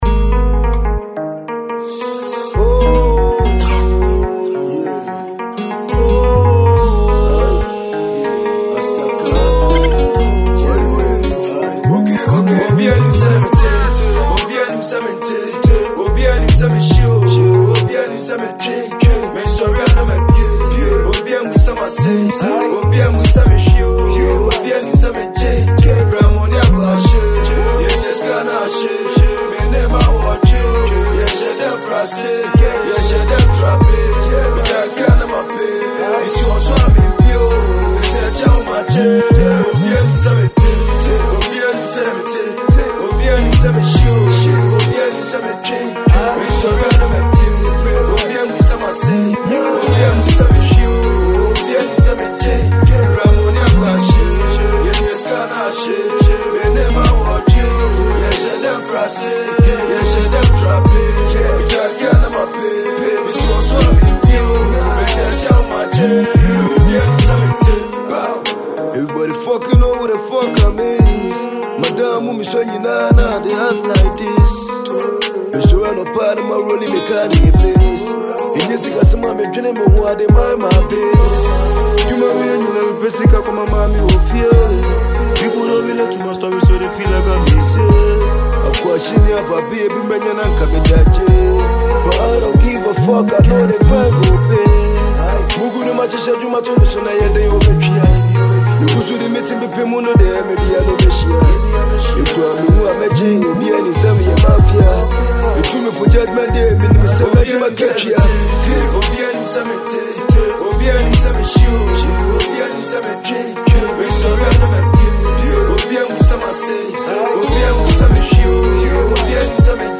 a high profile Ghanaian hip-pop and rap drill artist